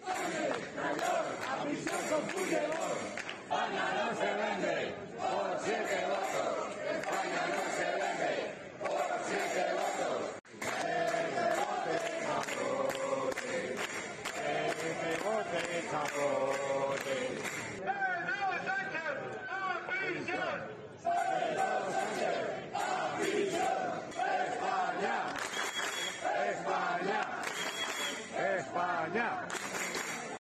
Manifestación sábado tarde frente a la sede del PSOE de Ávila